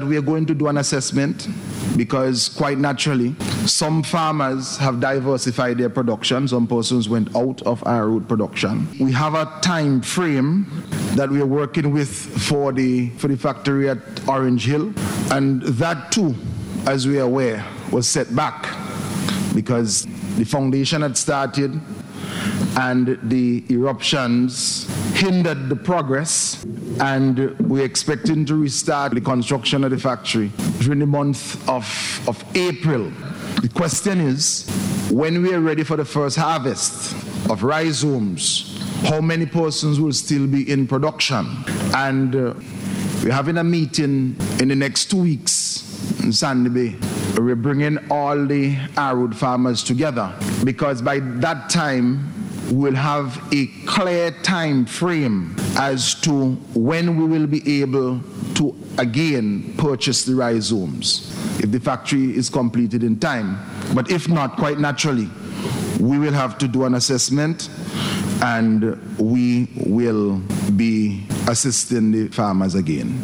Minister of Agriculture, Hon. Saboto Caesar made the disclosure in Parliament on Tuesday as he responded to a question from a Member of the Opposition.